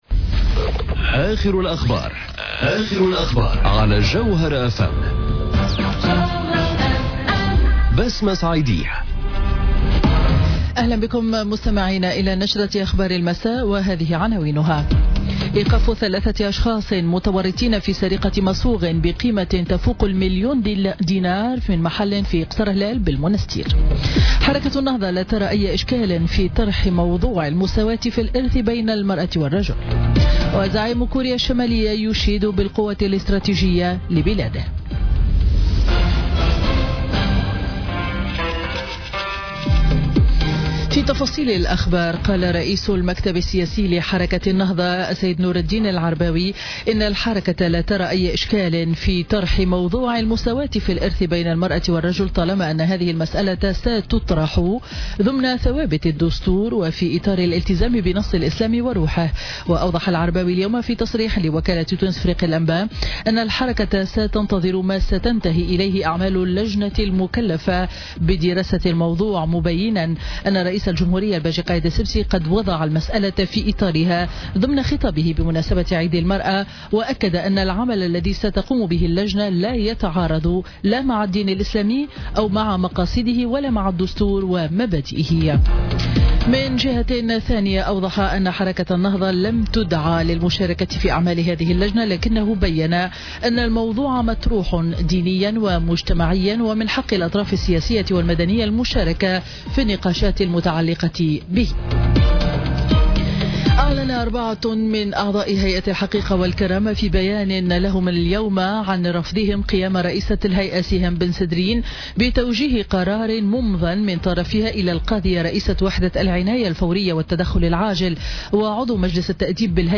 نشرة أخبار السابعة مساء ليوم الثلاثاء 15 أوت 2017